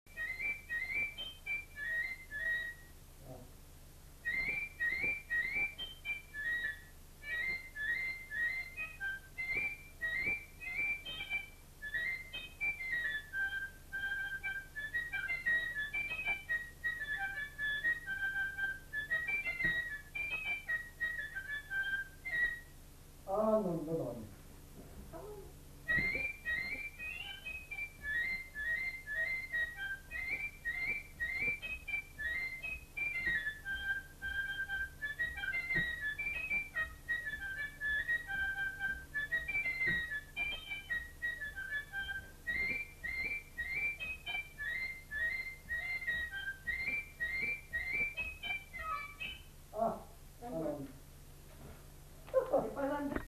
Répertoire d'airs à danser joué à la flûte à trois trous et à l'harmonica
Rondeau